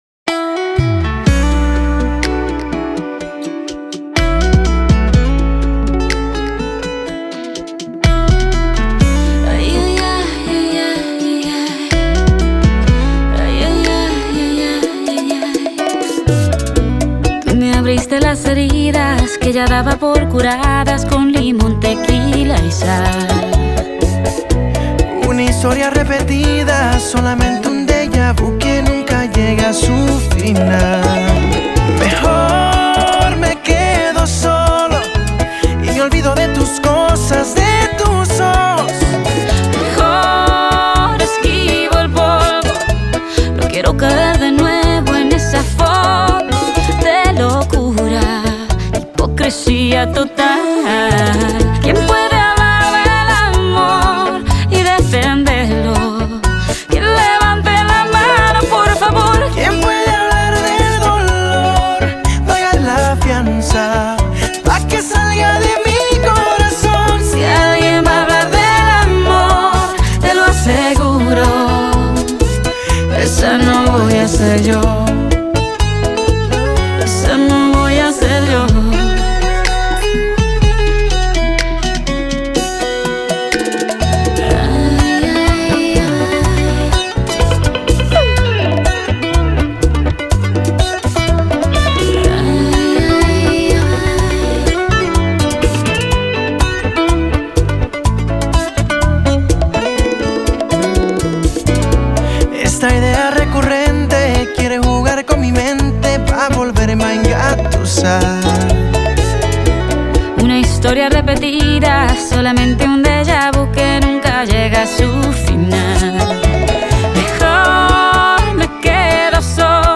ژانر: پاپ $ راک